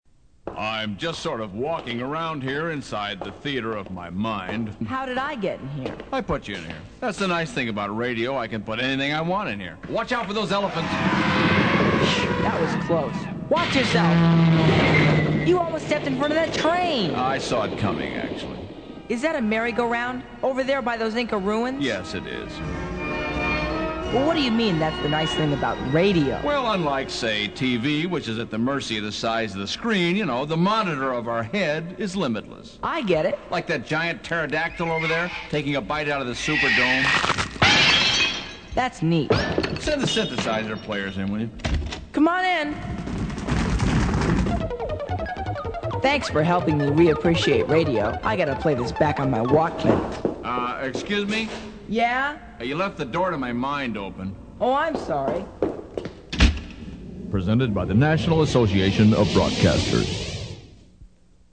Meanwhile, here are a few classic pro-Radio promos for your listening enjoyment (they are not authorized for airplay), courtesy of the Radio Advertising Bureau.